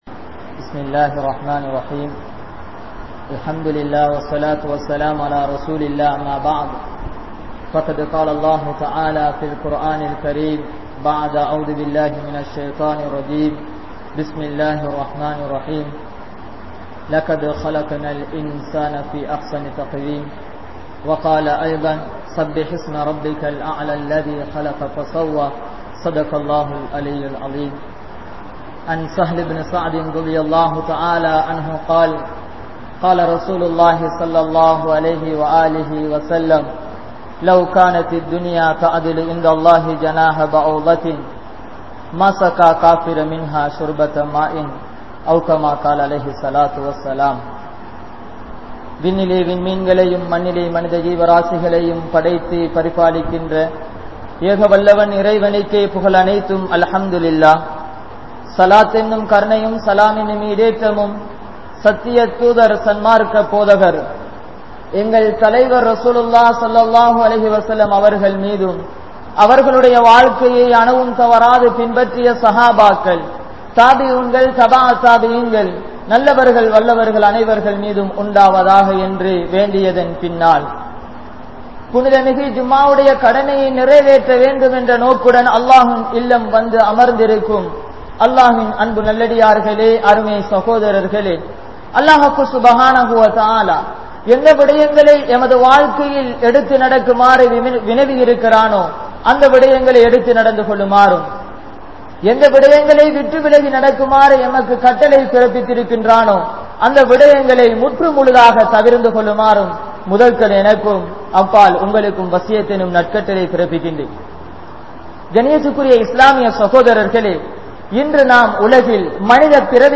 Manitha Padaippin Noakkam (மனித படைப்பின் நோக்கம்) | Audio Bayans | All Ceylon Muslim Youth Community | Addalaichenai
Jumua Masjith